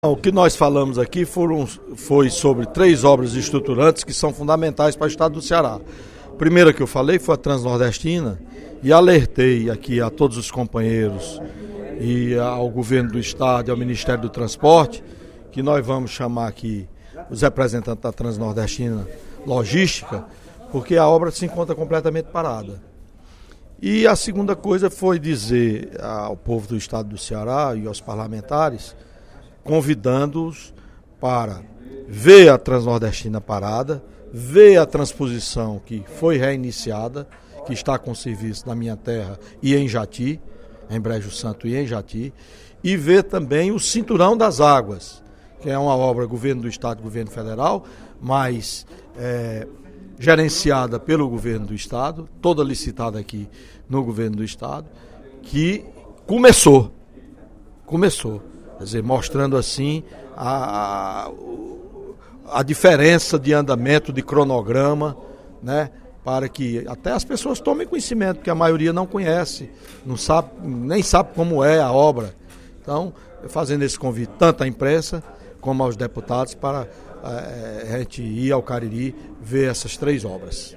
No primeiro expediente da sessão plenária desta sexta-feira (08/11), o deputado Welington Landim (Pros) propôs a criação de uma comissão especial para acompanhar o andamento das obras de transposição do rio São Francisco, do Cinturão das Águas e da Transnordestina.